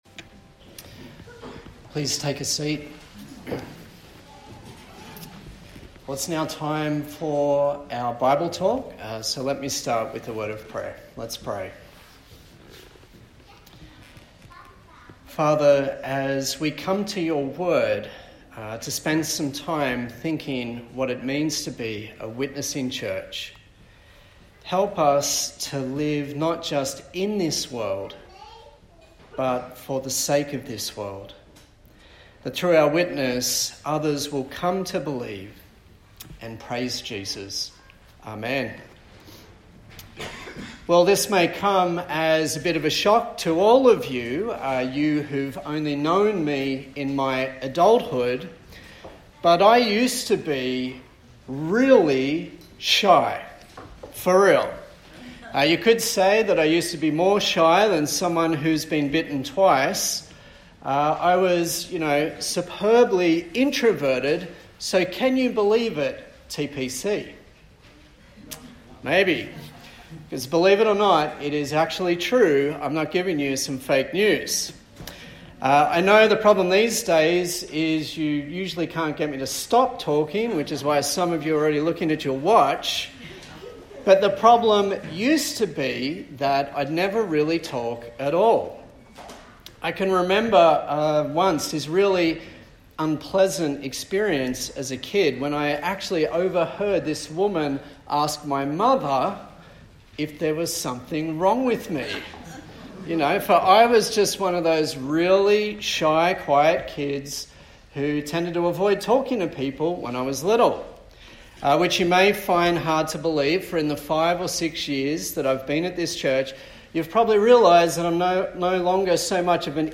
A sermon in the series on the book of Acts
Service Type: Sunday Morning